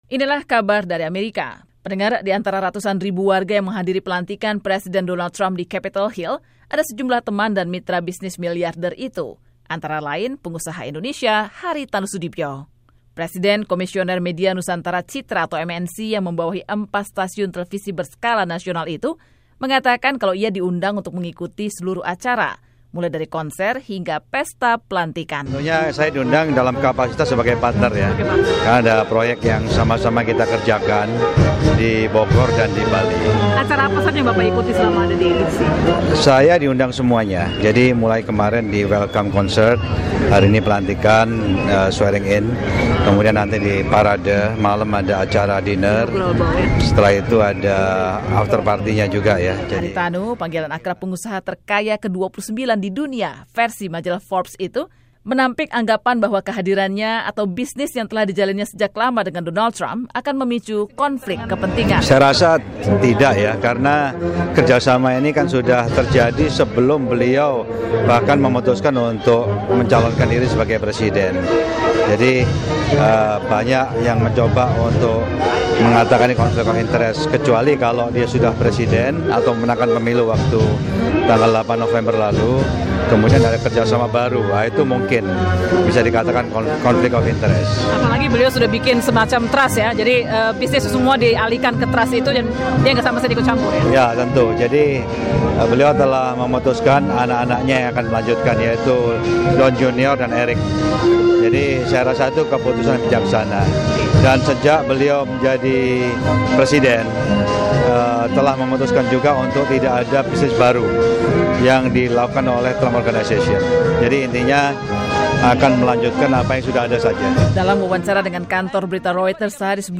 yang mewawancarainya seusai pelantikan Presiden Donald Trump.